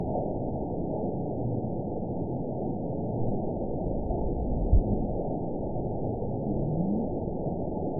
event 917346 date 03/28/23 time 20:49:03 GMT (2 years, 1 month ago) score 9.31 location TSS-AB05 detected by nrw target species NRW annotations +NRW Spectrogram: Frequency (kHz) vs. Time (s) audio not available .wav